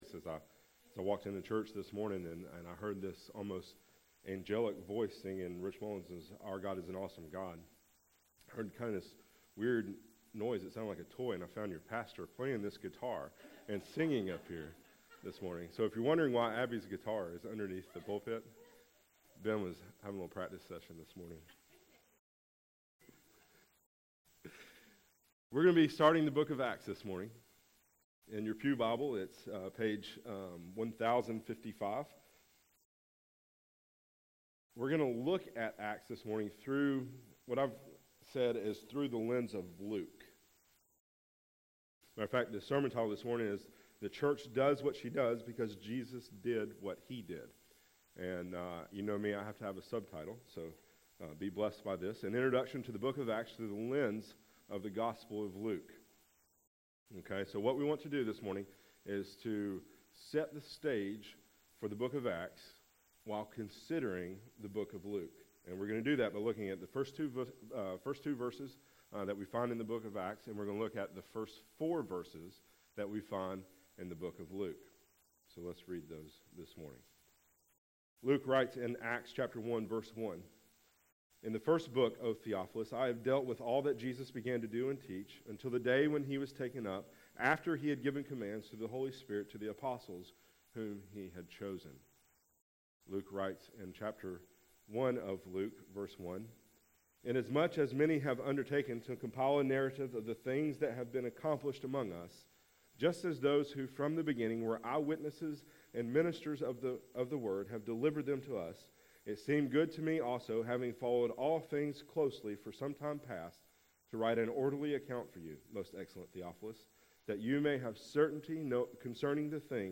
Before the sermon, the gathered read scripture and sang songs.
After the sermon, the congregation sang How Firm a Foundation in response.